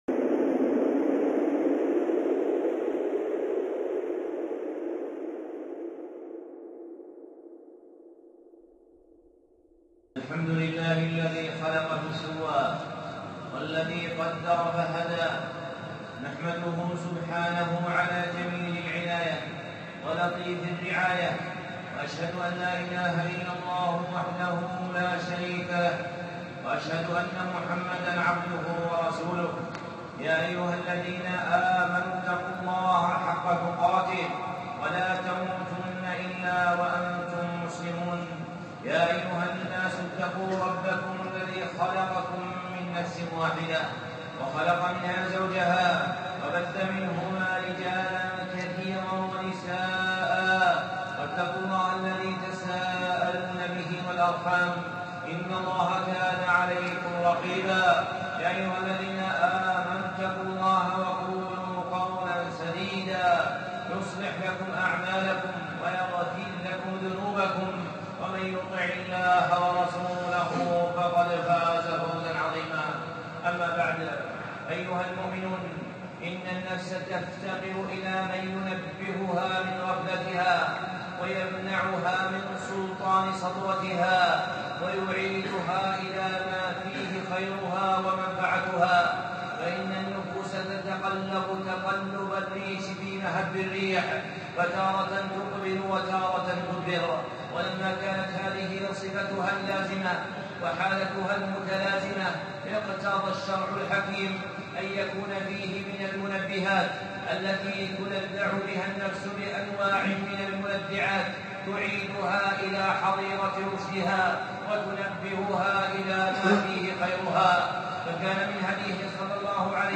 خطبة